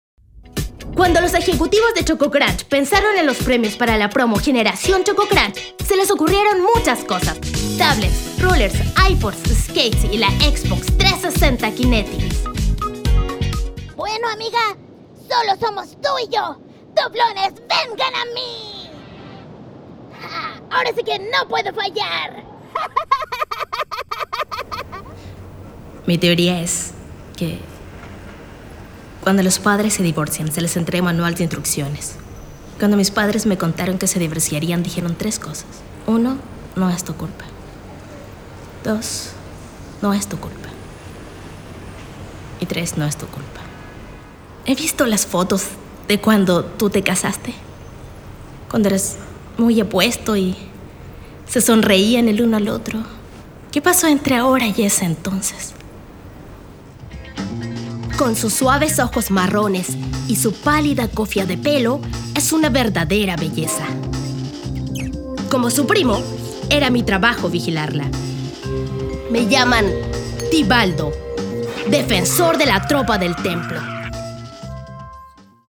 Reel Voz